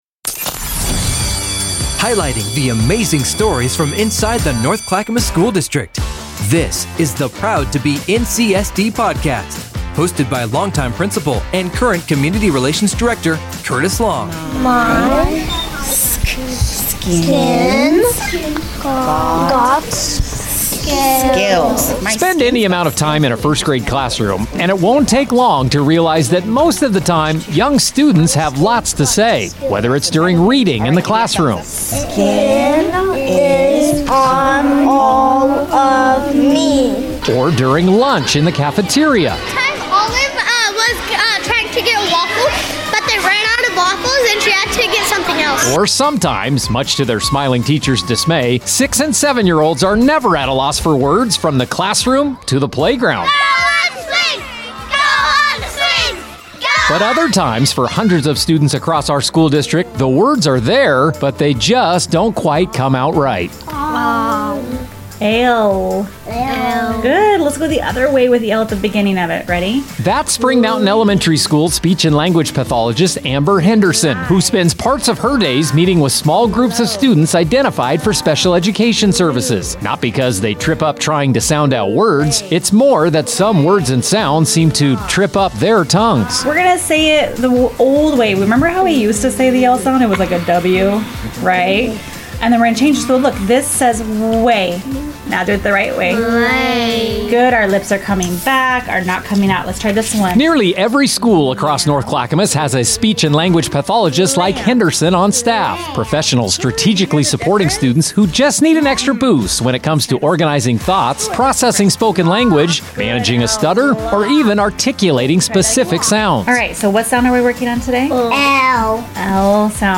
in this episode, we hear the voices of five NCSD SLPs